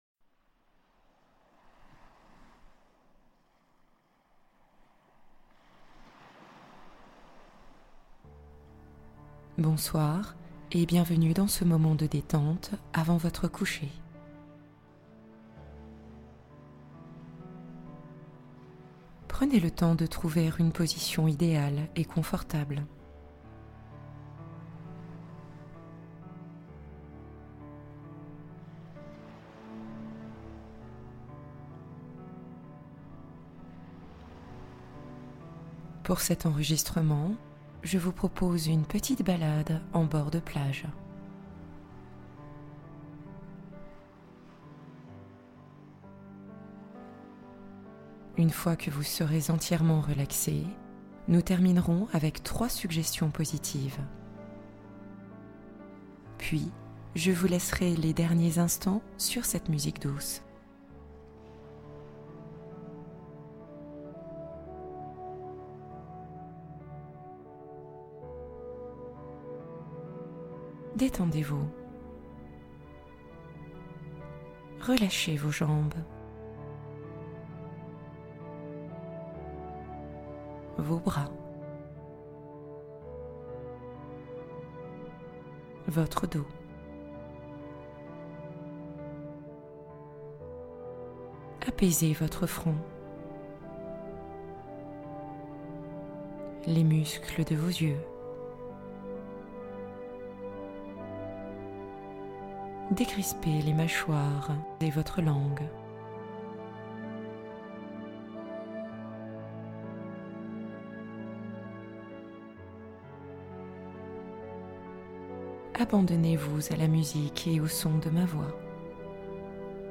Détox mentale avant le sommeil | Méditation nocturne pour libérer l’esprit